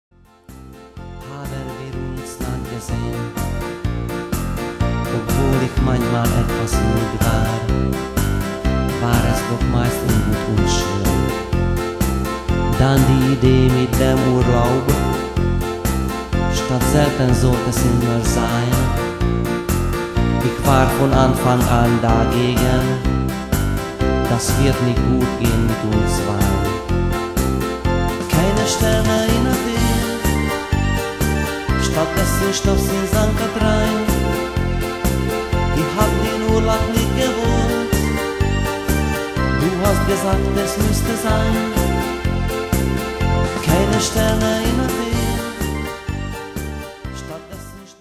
Programm mit meinem Gesang